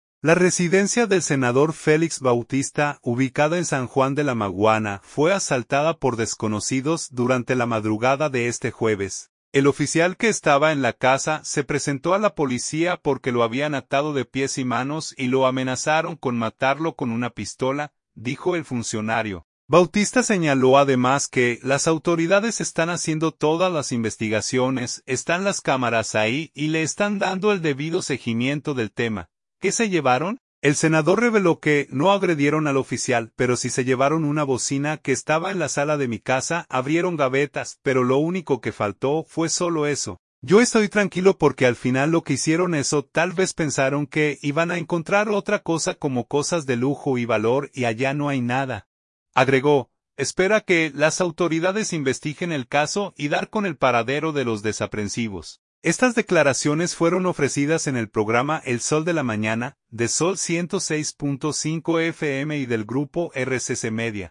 Estas declaraciones fueron ofrecidas en el programa El Sol de la Mañana, de Zol 106.5 FM y del grupo RCC Media.